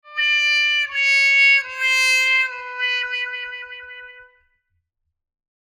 brass-fail-8-c-207132.wav